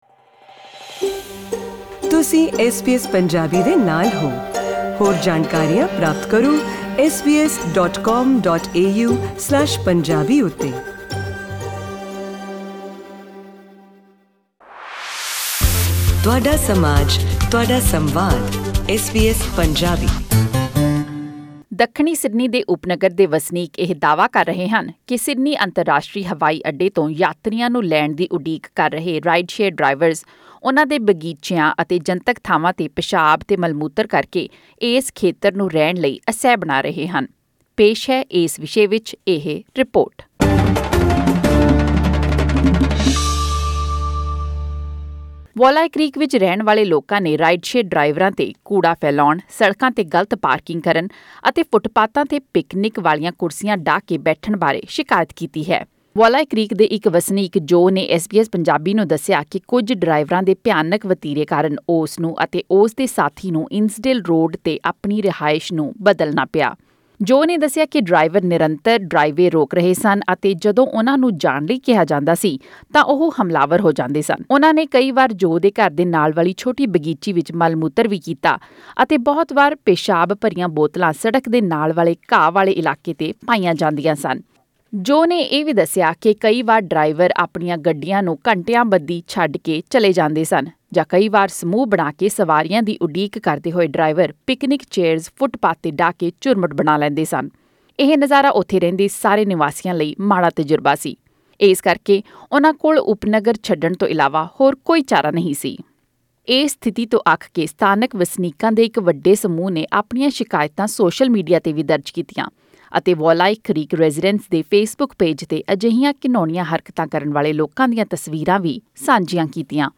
ਦੱਖਣੀ ਸਿਡਨੀ ਦੇ ਇਲਾਕੇ ਵੌਲੀ ਕ੍ਰੀਕ ਦੇ ਵਸਨੀਕ ਇਸ ਗੱਲ ਦੀ ਸ਼ਿਕਾਇਤ ਕਰ ਰਹੇ ਹਨ ਕਿ ਅੰਤਰਾਸ਼ਟਰੀ ਹਵਾਈ ਅੱਡੇ ਤੋਂ ਸਵਾਰੀਆਂ ਚੁੱਕਣ ਆਓਂਦੇ ਕੁਝ ਰਾਈਡ-ਸ਼ੇਅਰ ਡਰਾਈਵਰ ਉਸ ਇਲਾਕੇ ਵਿਚਲੀਆਂ ਜਨਤਕ ਥਾਵਾਂ 'ਤੇ ਪਿਸ਼ਾਬ ਕਰਦੇ ਹਨ ਅਤੇ ਸੜਕਾਂ ਉੱਤੇ ਇੱਧਰ -ਉੱਧਰ ਘੁੰਮਦੇ ਹਨ ਜੋਕਿ ਉਹਨਾਂ ਲਈ ਬਰਦਾਸ਼ਤ ਤੋਂ ਬਾਹਰ ਦੀ ਗੱਲ ਹੈ। ਪੇਸ਼ ਹੈ ਇਸ ਬਾਰੇ ਇਹ ਵਿਸ਼ੇਸ਼ ਆਡੀਓ ਰਿਪੋਰਟ....